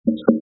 Eléments de réponse dans cet entretien